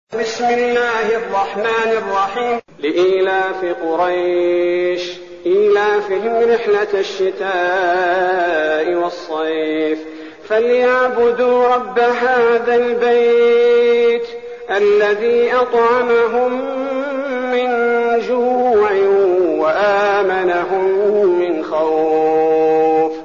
المكان: المسجد النبوي الشيخ: فضيلة الشيخ عبدالباري الثبيتي فضيلة الشيخ عبدالباري الثبيتي قريش